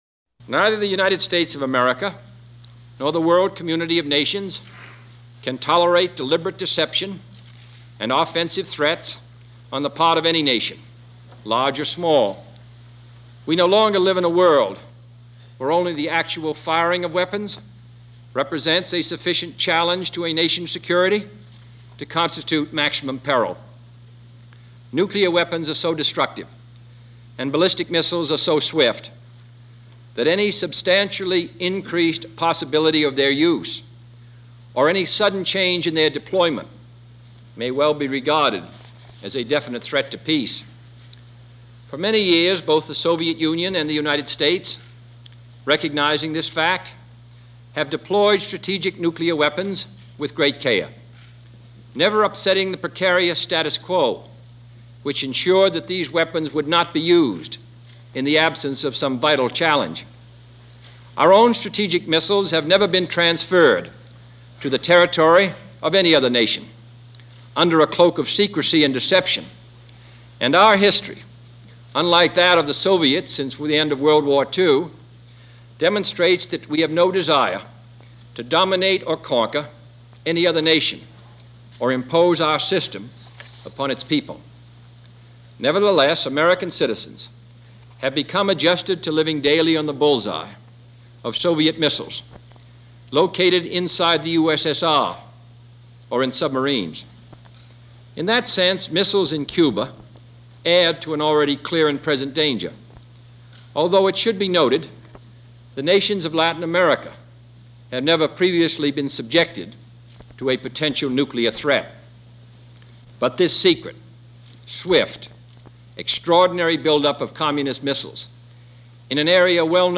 taken fromRadio and television report to the American people on the Soviet Arms Buildup in Cuba
President John F.Kennedy
The White House, October 22, 1962
Great Speeches - JFK- The Cuban Missile Crisis.mp3